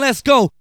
VOX SHORTS-2 0008.wav